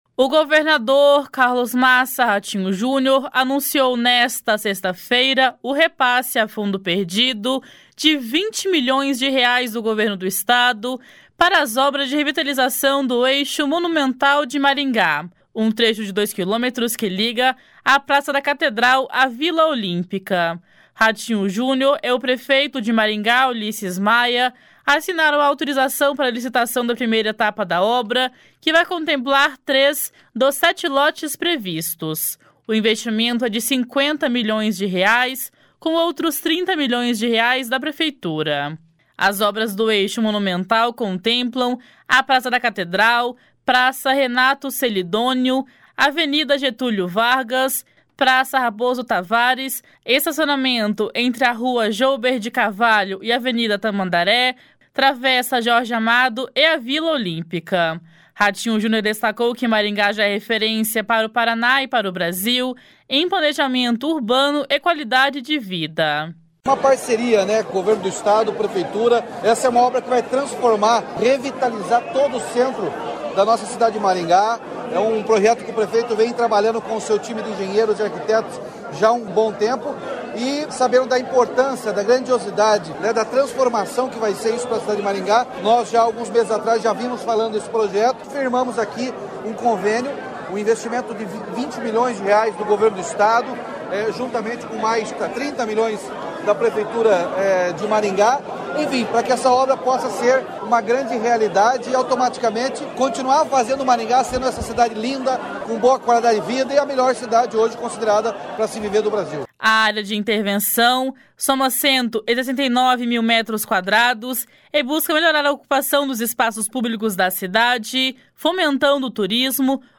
// SONORA RATINHO JUNIOR //
// SONORA ULISSES MAIA //